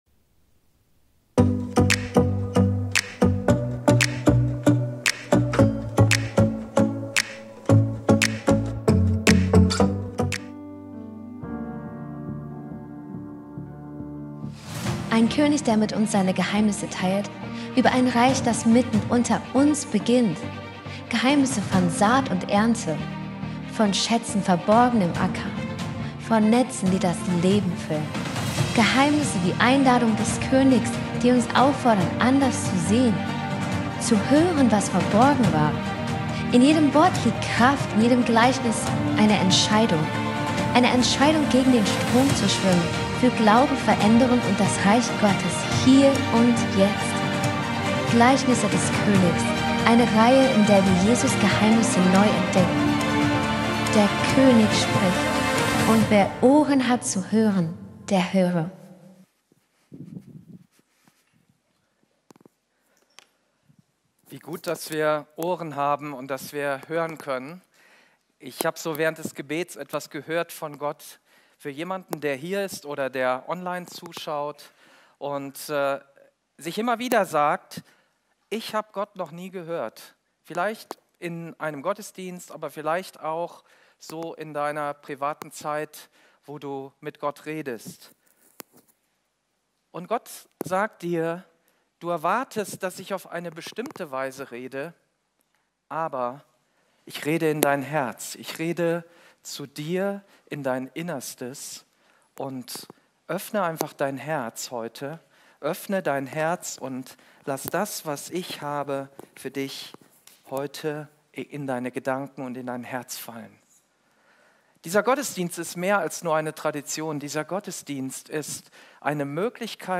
Live-Gottesdienst aus der Life Kirche Langenfeld.
Kategorie: Sonntaggottesdienst Predigtserie: Die Gleichnisse des Königs